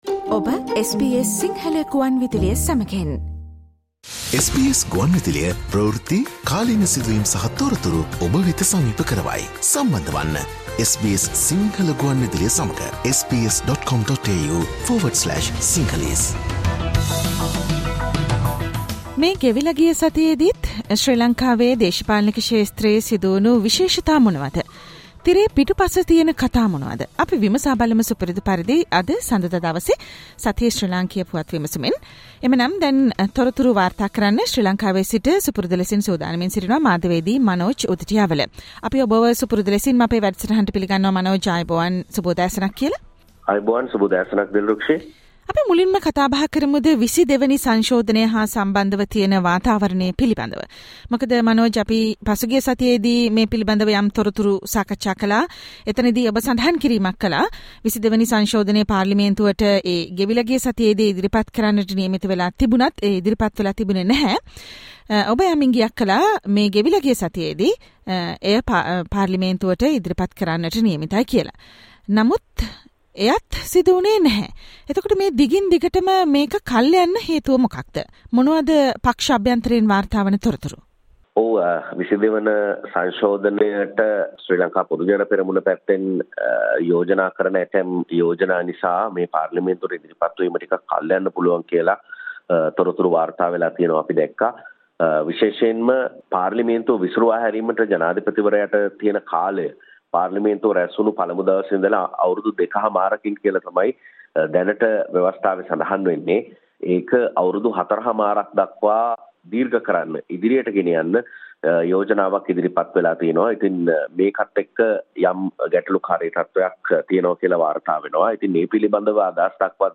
Weekly Sri Lankan political News wrap of the week_ Ranil Wickramasinghe and the 22nd constitutional amendment